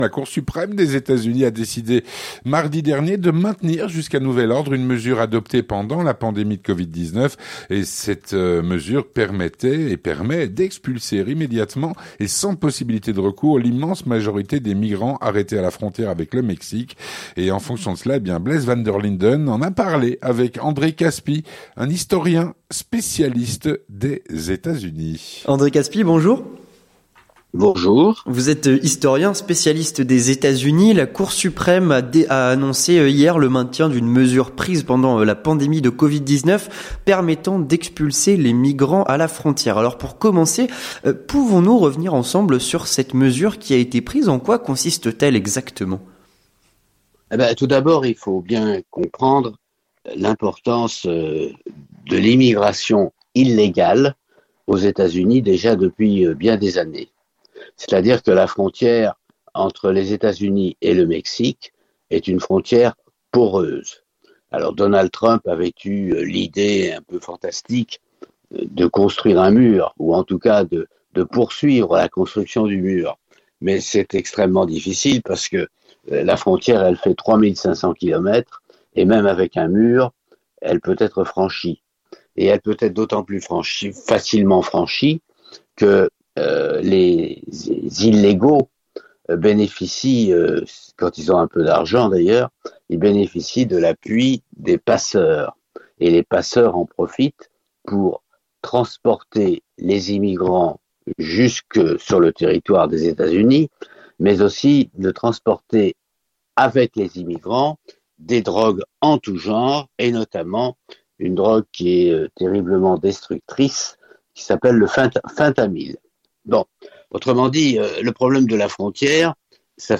L'Entretien du Grand Journal - avec André Kaspi, historien spécialiste des Etats-Unis